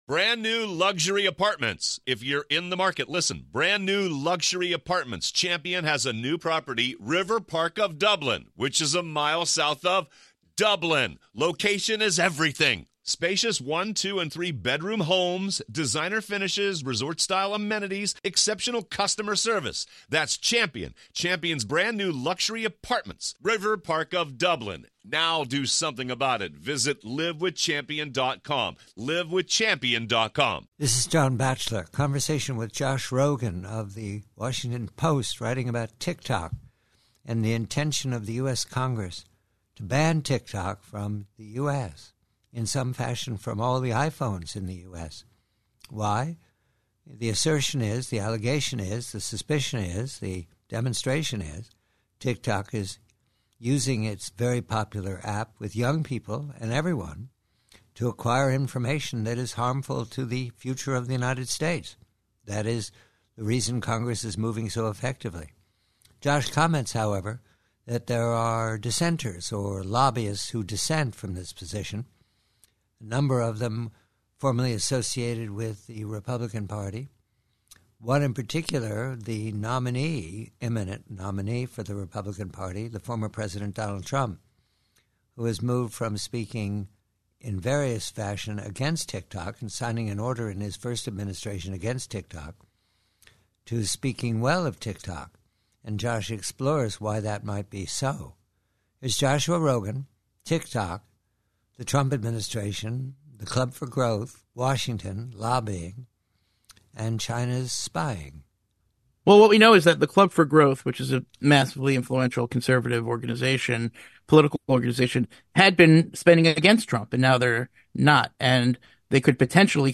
PREVIEW: Banning TikTok: conversation with colleague Josh Rogin of the Washington Post re the lobbying in Washington (former President Trump is mentioned as friend of TikTok) to preserve TikTok from the intention of Congress to force sale and supervsion b